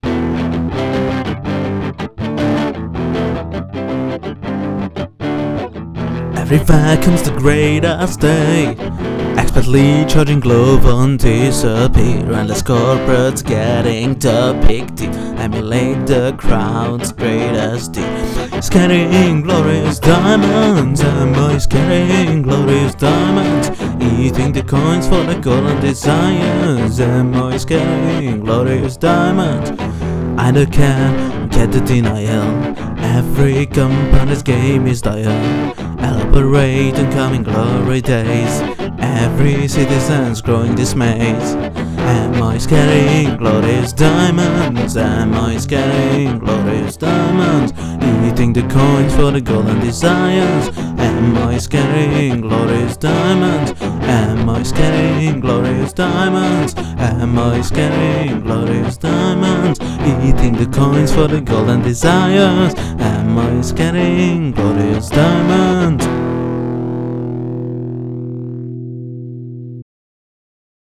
Content warning: oc music, unserious pre-pre-alpha demo
enjoying Em C G D in your songs?